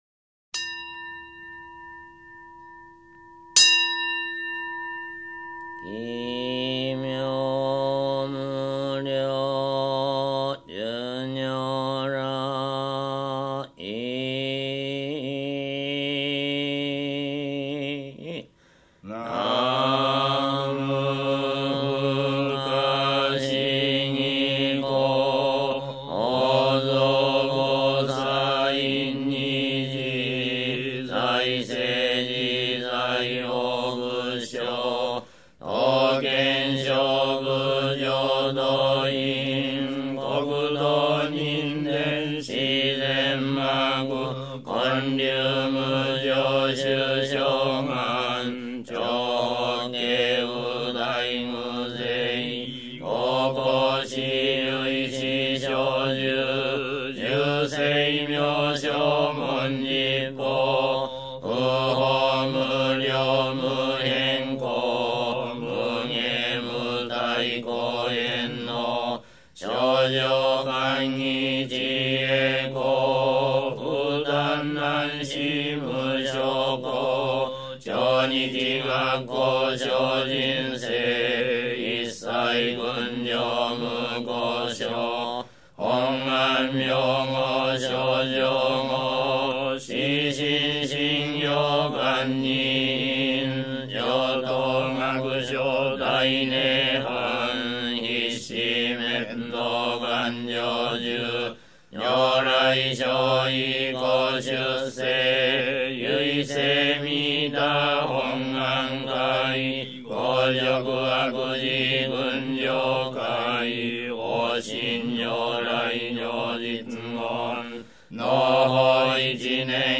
真宗 東本願寺門信徒勤行